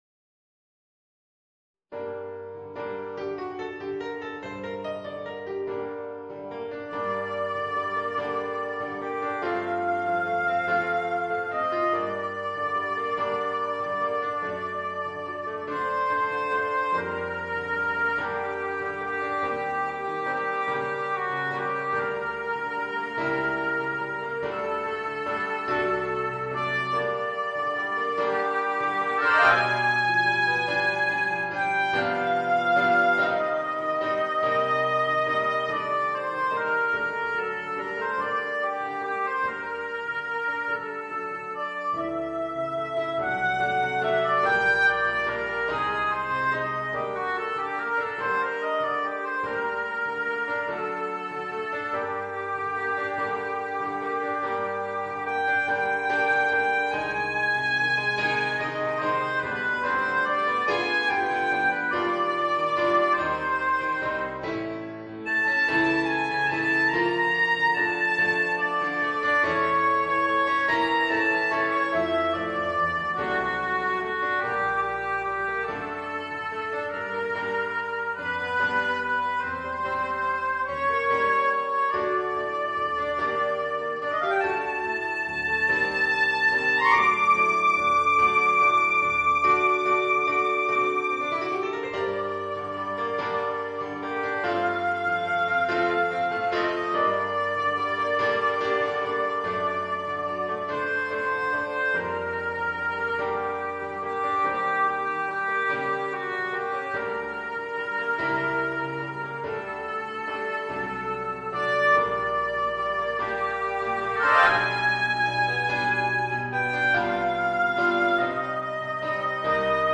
Voicing: Oboe and Piano